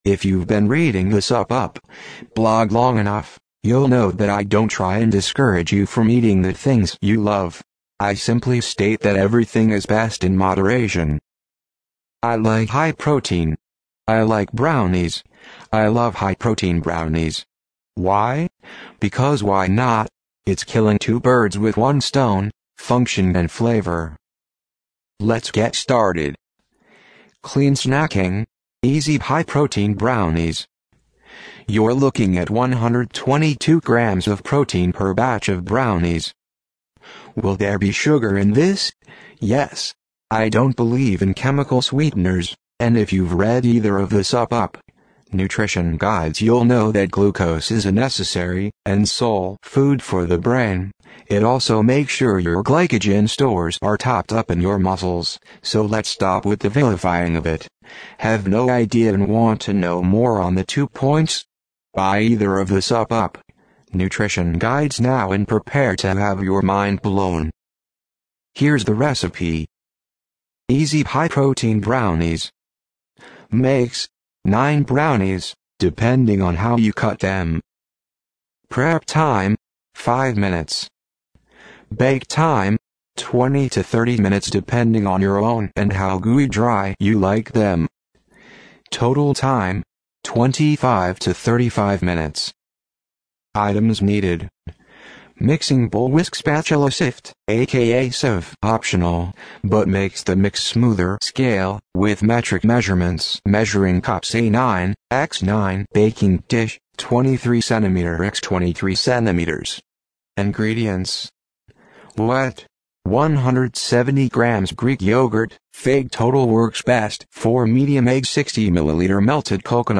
SUPP UP. TTS – Click below to listen to the audio version of this post now:
Clean-Snacking-Easy-High-Protein-Brownies-SUPP-UP.-Blog-TTS.mp3